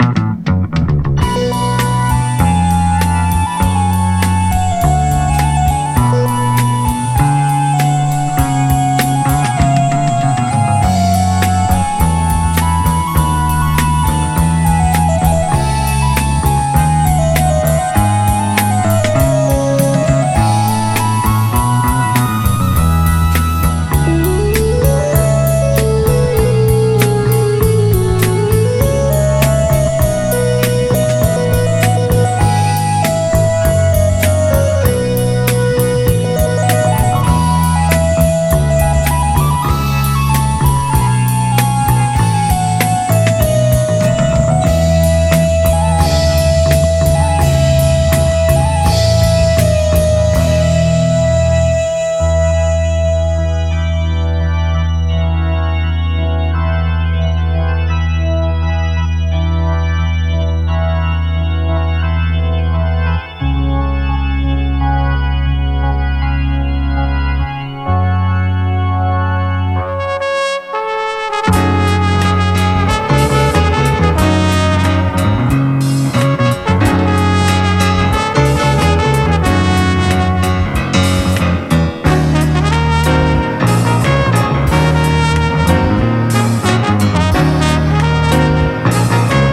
幅広い音楽性の英ジャズ・ロック・バンド！